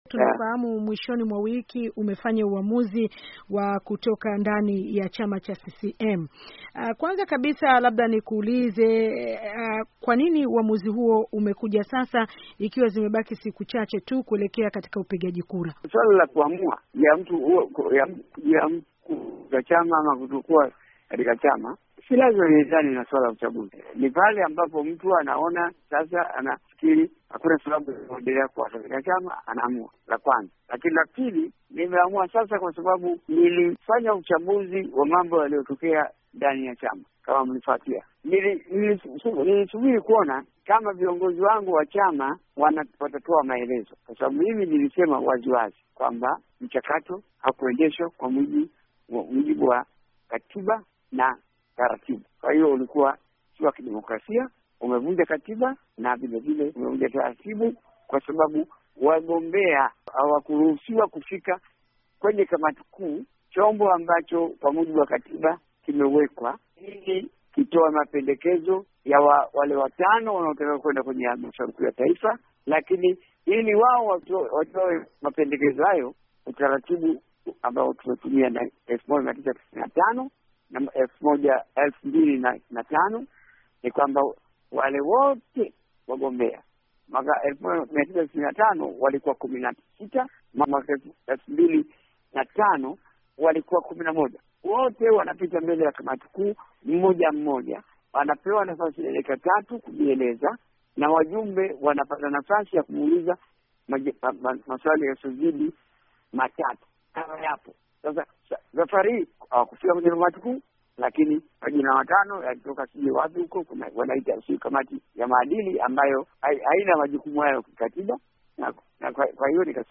Mahojiano na aliyekuwa kiongozi wa CCM Ngombale-Mwiru Tanzania